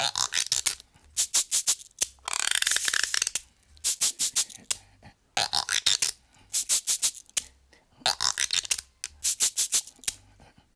88-PERC12.wav